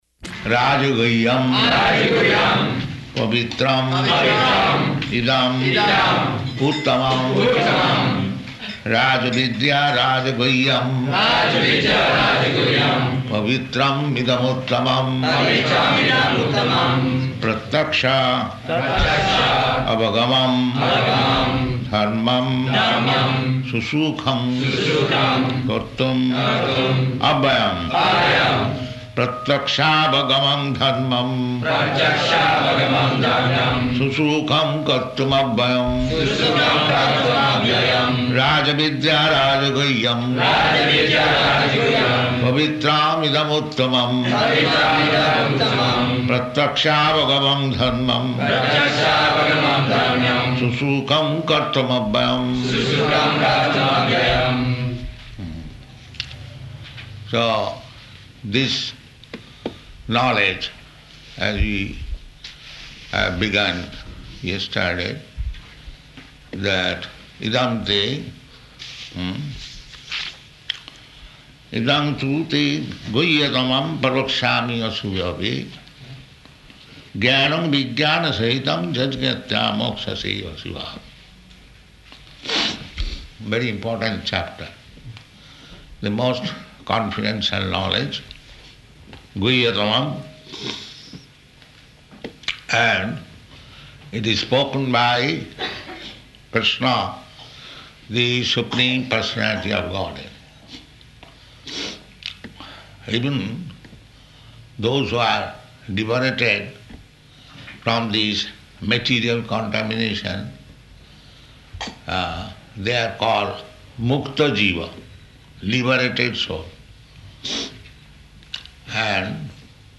Location: Melbourne
[leads devotees in chanting]